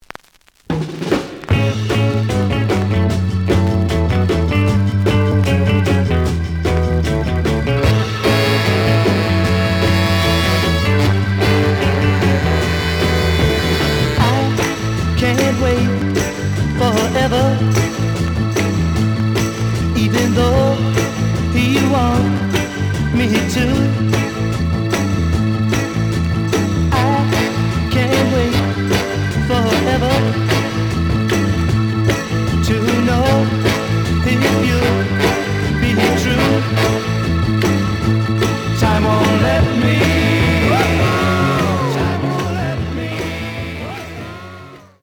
●Genre: Rock / Pop